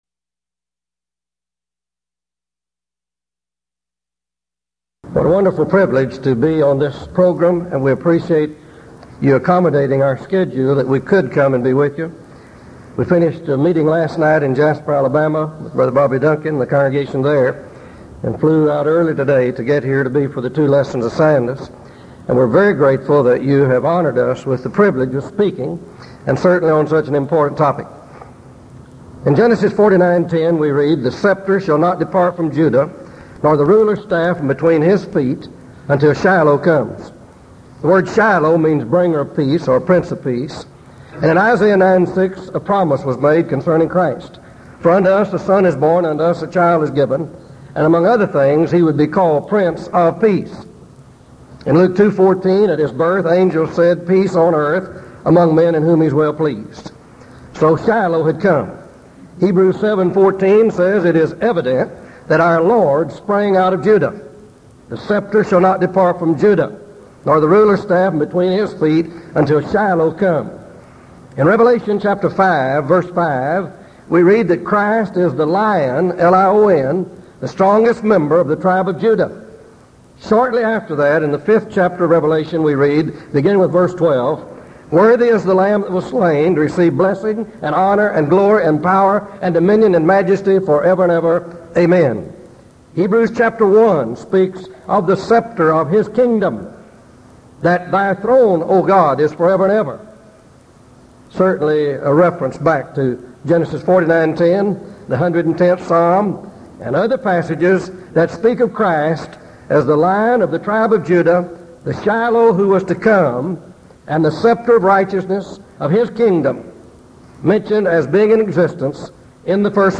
Denton Lectures Event: 1982 Denton Lectures